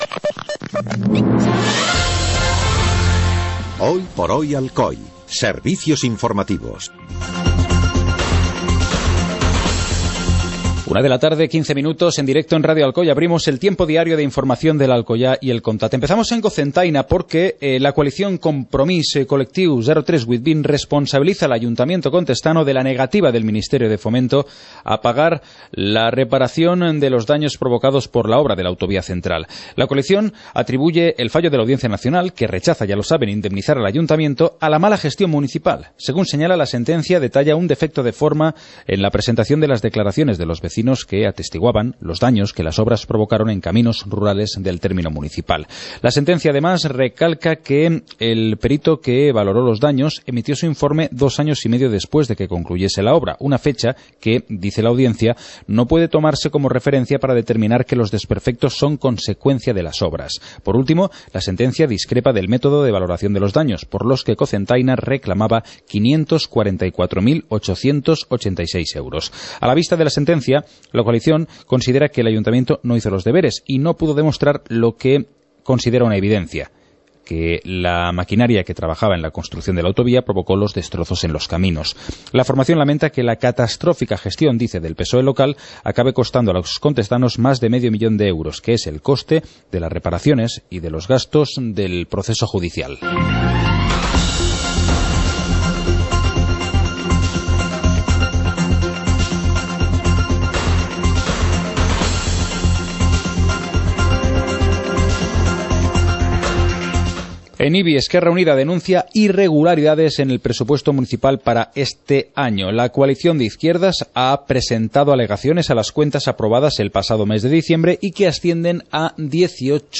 Informativo comarcal - jueves, 08 de enero de 2015